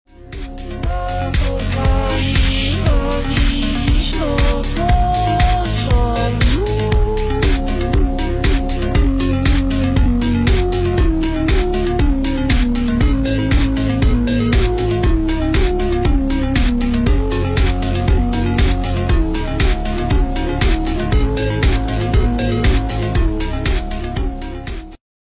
SCP-1981 appears to be a home video recording of former United States President Ronald Reagan delivering his "Evil Empire" speech to the National Association of Evangelicals at Sheraton Twin Towers Hotel, Orlando, FL on 3/8/1983. However, at 1 minute and 10 seconds, the speech begins to deviate heavily, eventually resembling no known speech ever made by Reagan.